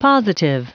Prononciation du mot positive en anglais (fichier audio)
Prononciation du mot : positive